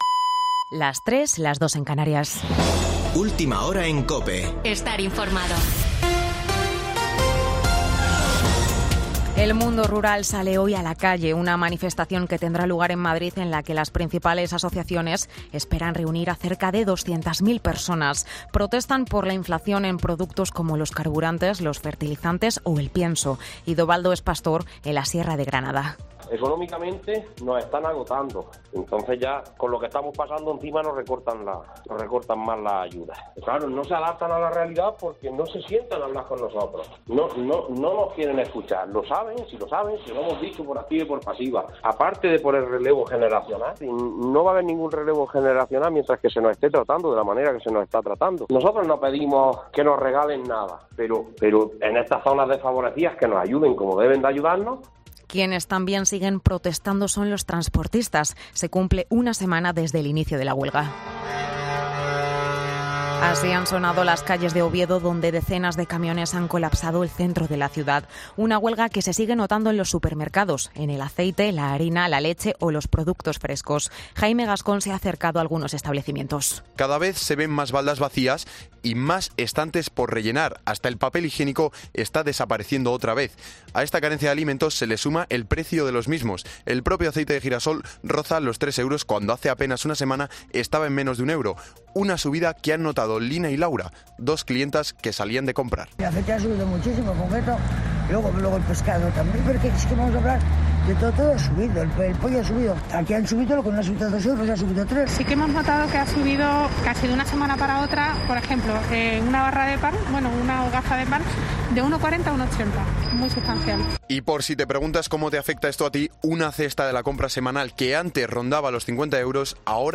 Boletín de noticias COPE del 20 de marzo de 2022 a las 3.00 horas